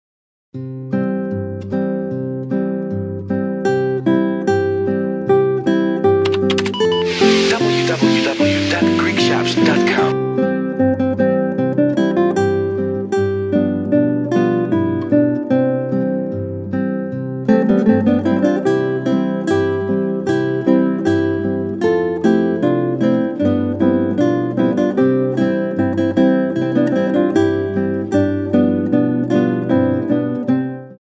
Instrumental Lullabies included on the CD: